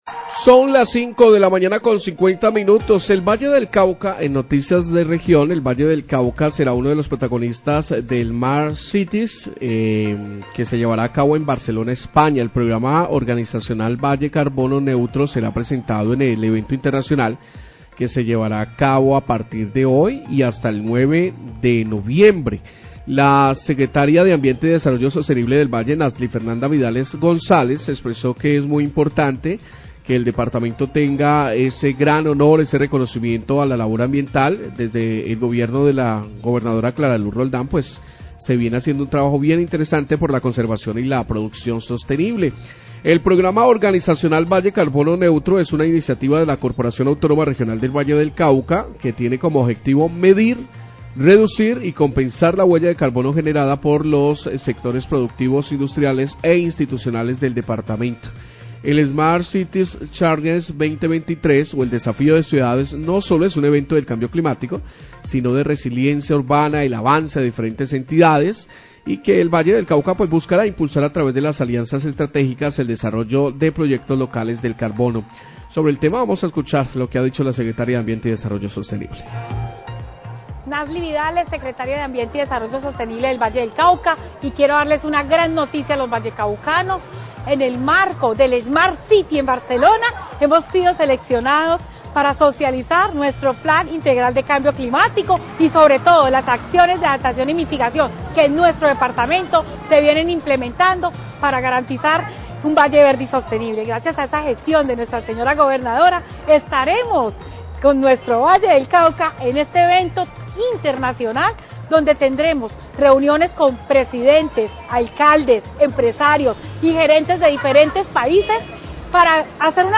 Radio
El gobierno del Valle del Cauca presentará en Barcelona en el marco del evento "Smart City Challenge",el programa "Valle Carbono Neutro", una iniciativa de la CVC. Declaraciones de la Secretaria de Ambiente y Desarrollo Sostenible, Nasly Vidales.